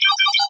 alert.ogg